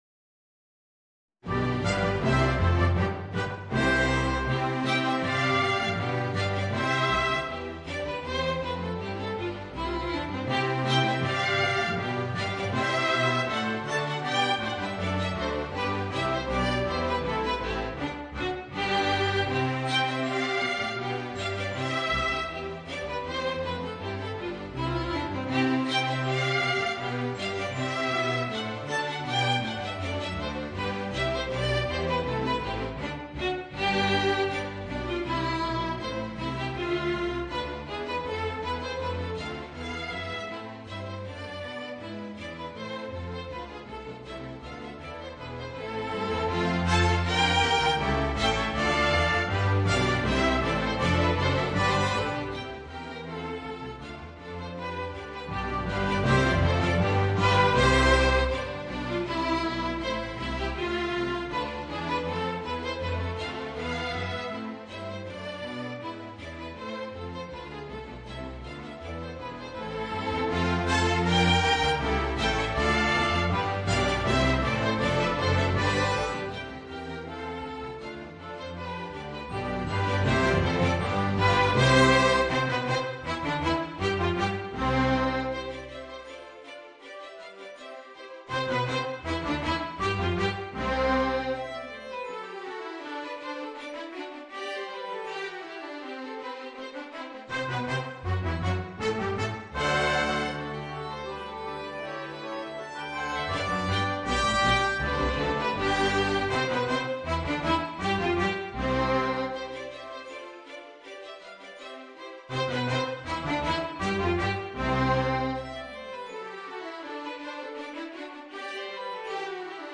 Voicing: Violoncello and Orchestra